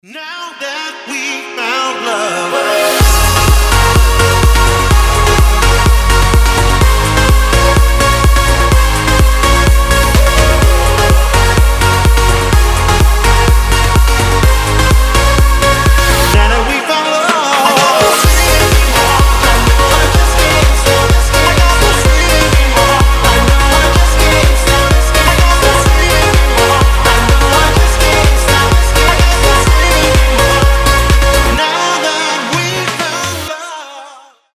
мужской голос
громкие
EDM
progressive house